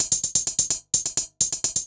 Index of /musicradar/ultimate-hihat-samples/128bpm
UHH_ElectroHatD_128-01.wav